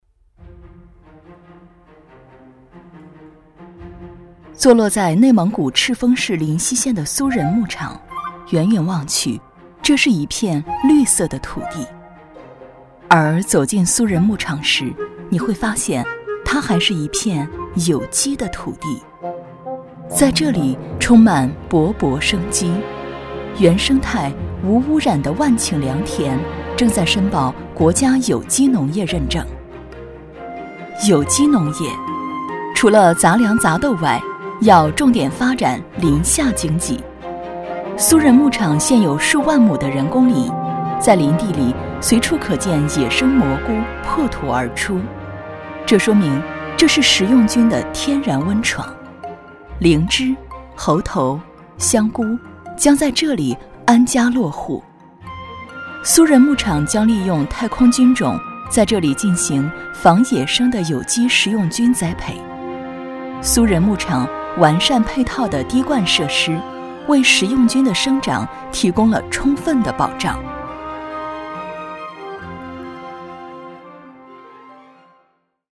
国语配音
女267-专题-高科技的苏人牧场.mp3